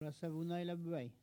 Elle provient de Bouin.
Catégorie Locution ( parler, expression, langue,... )